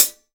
Index of /90_sSampleCDs/AKAI S6000 CD-ROM - Volume 3/Hi-Hat/12INCH_LIGHT_HI_HAT